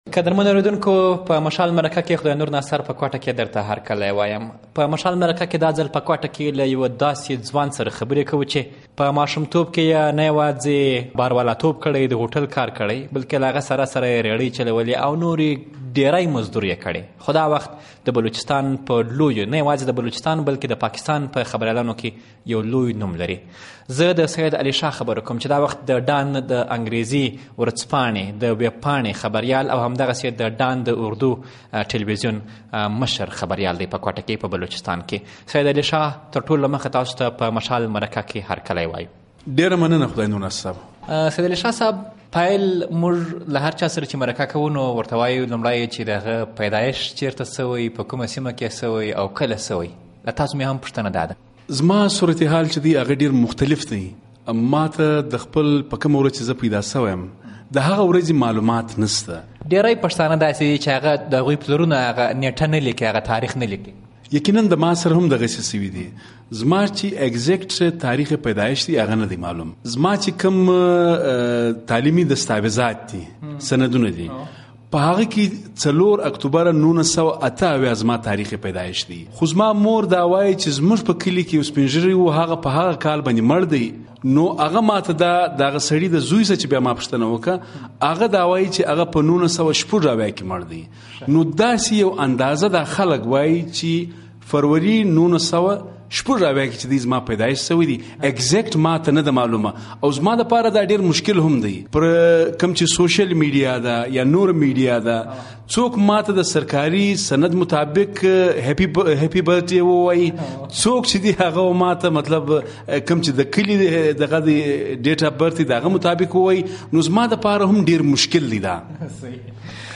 مشال مرکه